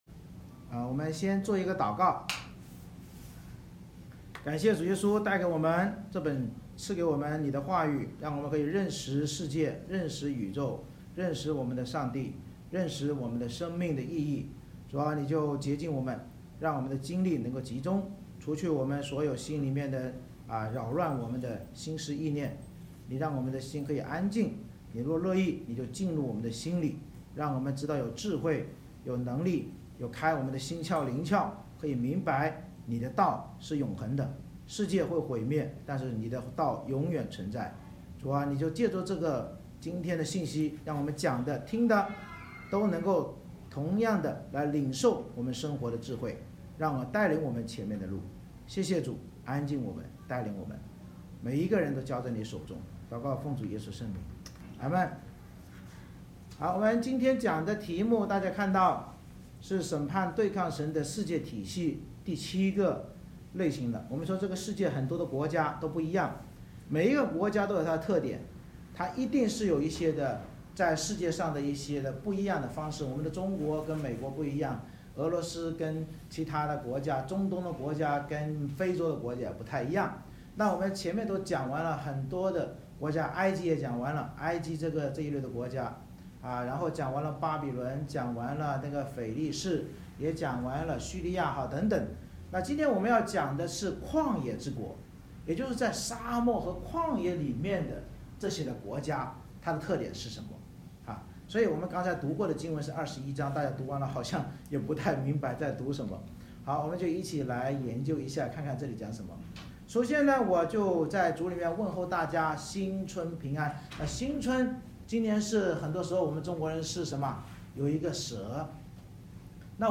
以赛亚书21:1-17 Service Type: 主日崇拜 先知以赛亚领受旷野的默示，警告我们不要像旷野沙漠之国那样骄傲地朝三暮四而活在黑暗中，否则一切荣耀必尽失。